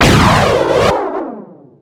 rocket.mp3